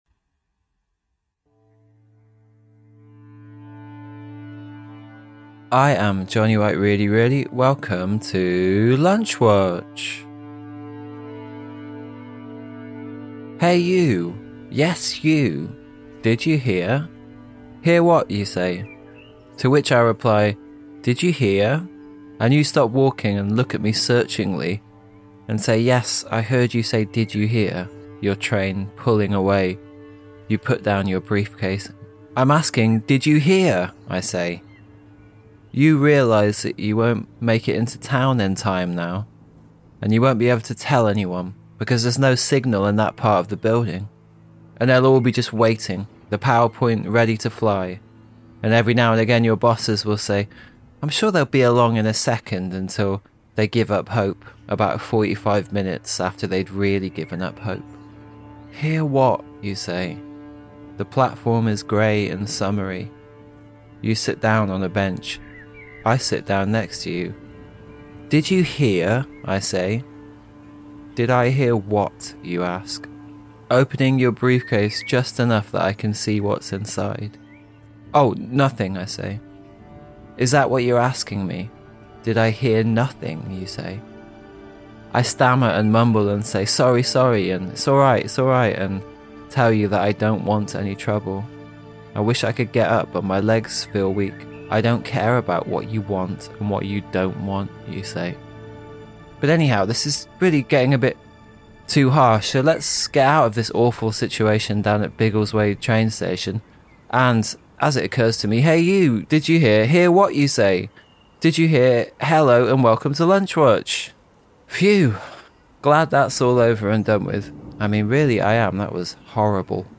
With original music